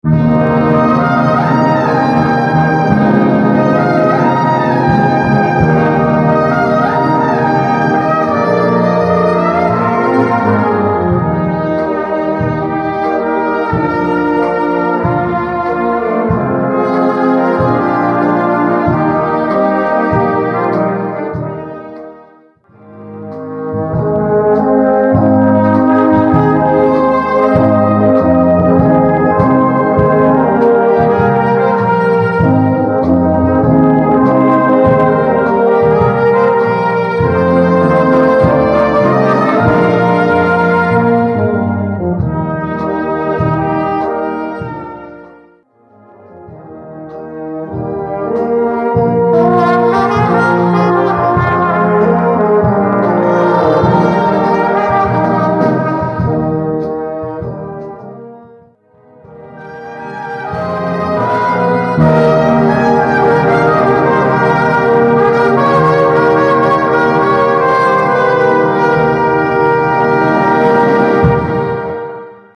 Solo für Trompete und Blasorchester
Besetzung: Blasorchester